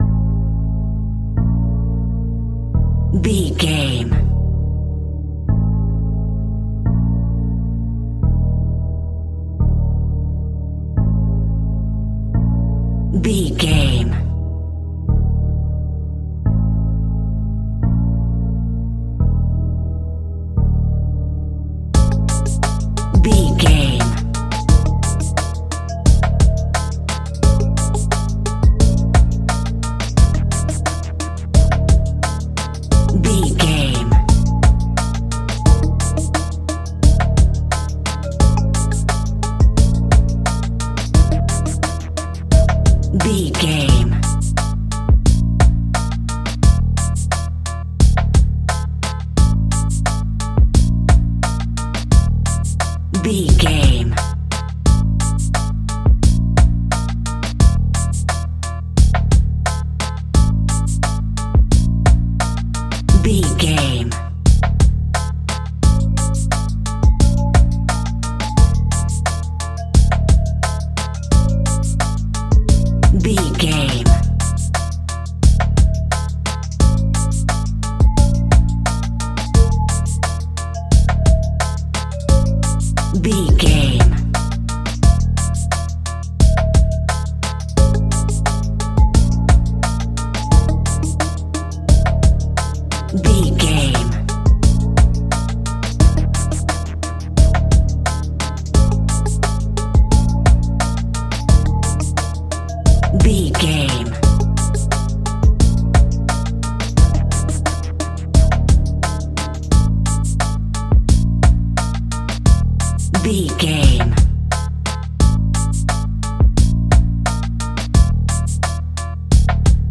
Aeolian/Minor
hip hop
chilled
laid back
groove
hip hop drums
hip hop synths
piano
hip hop pads